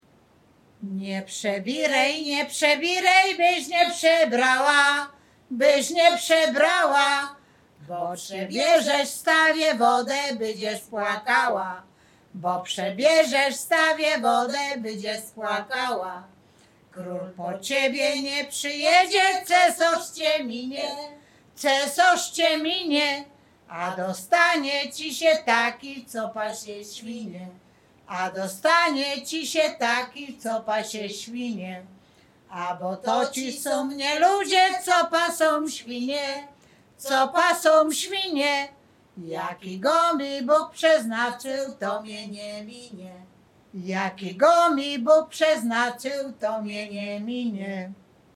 liryczne miłosne